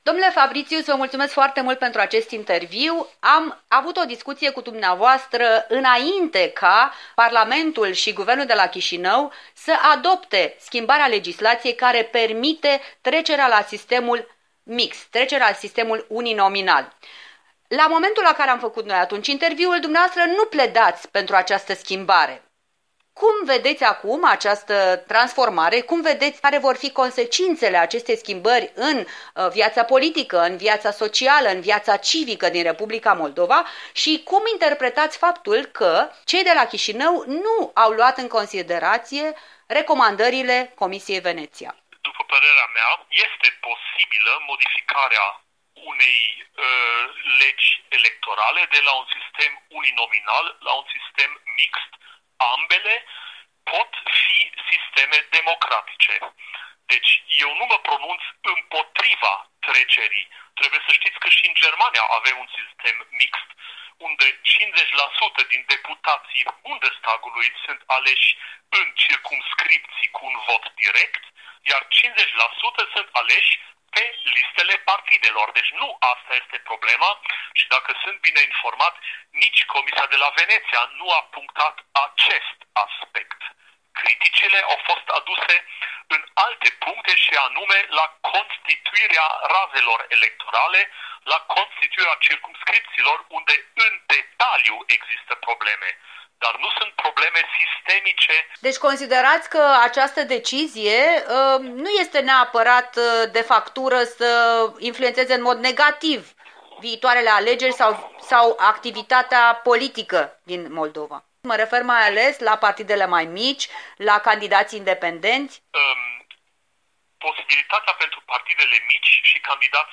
Un interviu cu omul politic german (CSU-Uniunea Creștin Socială), membru al Bundestagului și al Adunării Parlamentare a Consiliului Europei.
Un interviu la Strasbourg cu parlamentarul german Bernd Fabritius